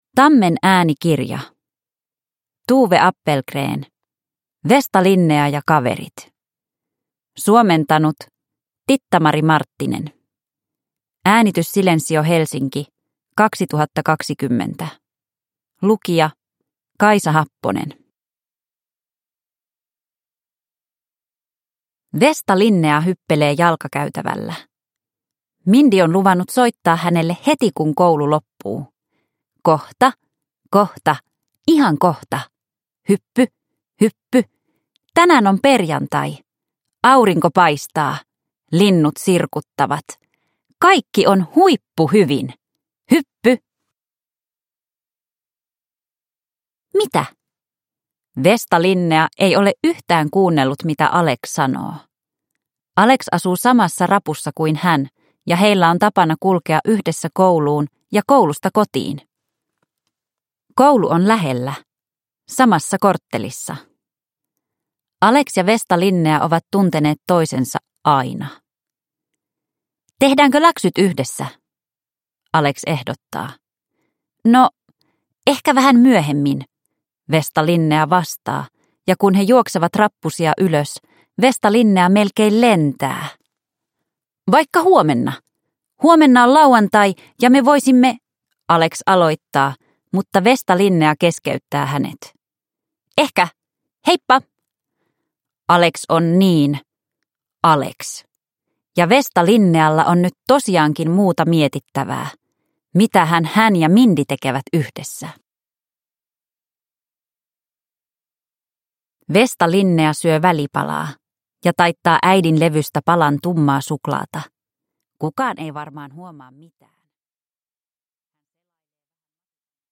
Vesta-Linnea ja kaverit – Ljudbok – Laddas ner